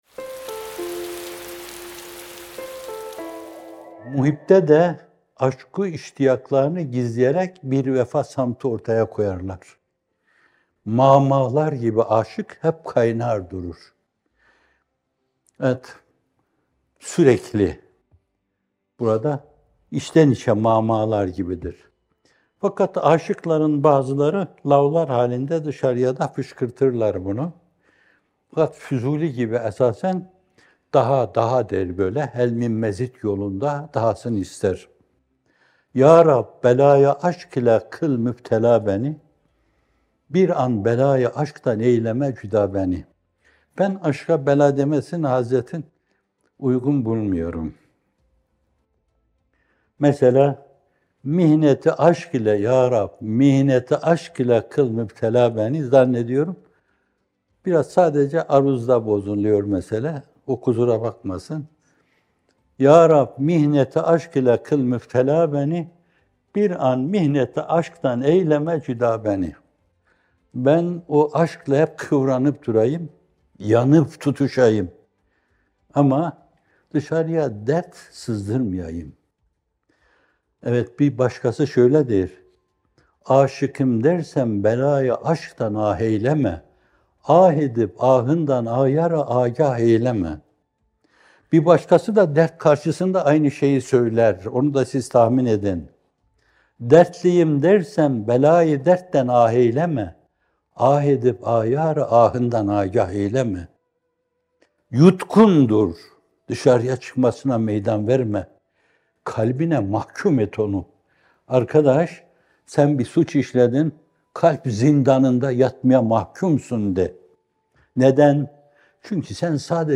İkindi Yağmurları – İçten İçe Fokur Fokur - Fethullah Gülen Hocaefendi'nin Sohbetleri